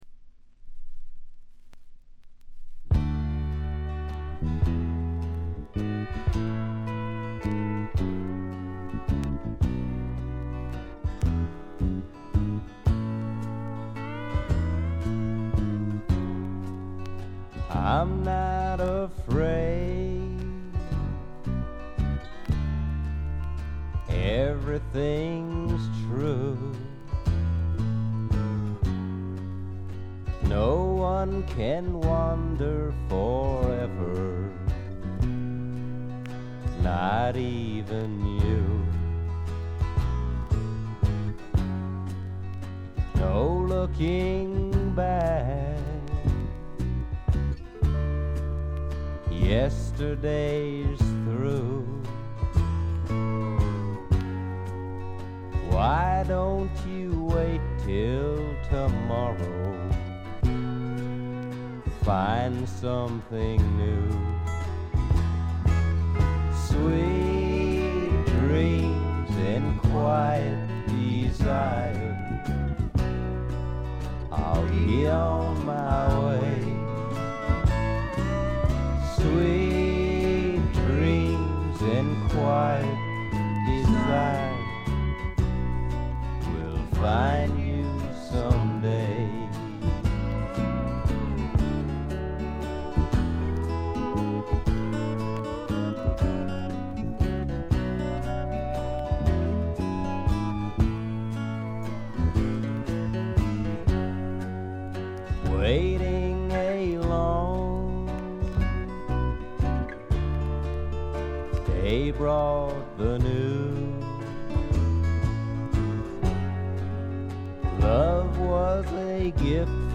軽微なバックグラウンドノイズにチリプチ少々。
試聴曲は現品からの取り込み音源です。